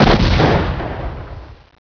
explo2.wav